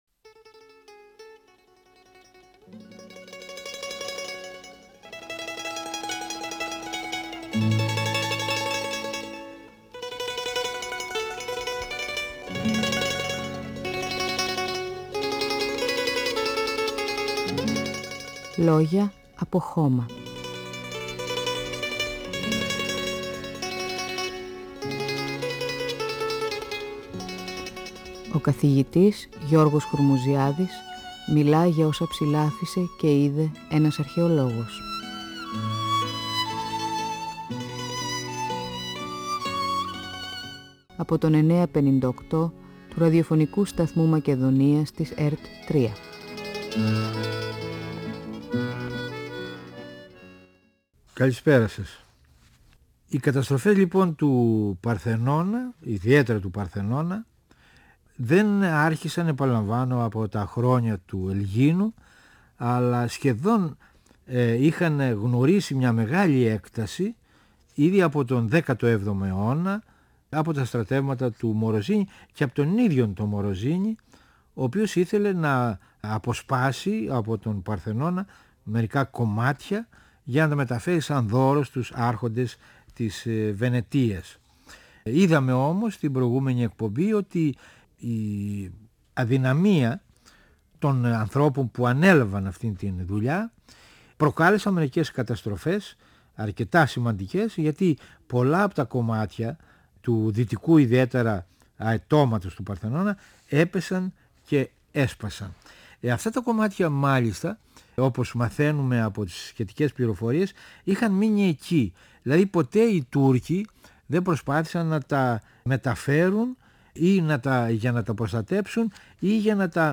ΦΩΝΕΣ ΑΡΧΕΙΟΥ του 958fm της ΕΡΤ3